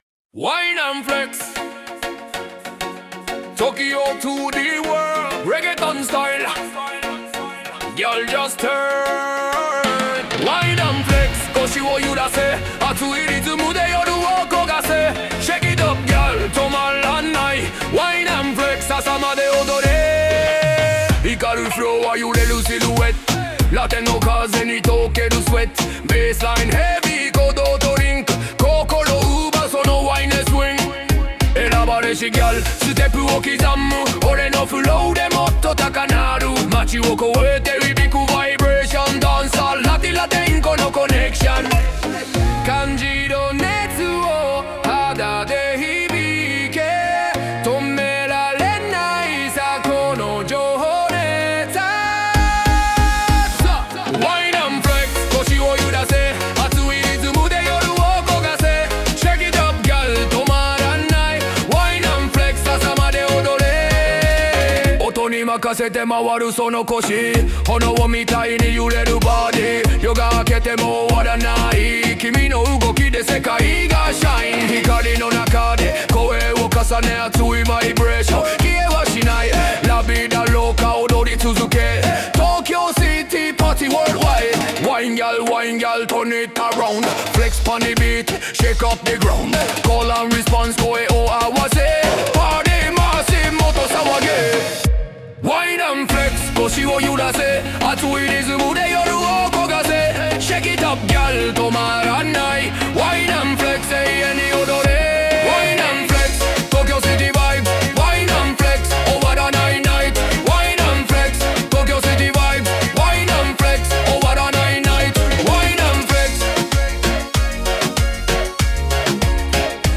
Dancehall × Reggaeton のフュージョンRiddim。BPM 96 / Key A#m
参考：AI歌入り音源　※小節構成の参考にしてください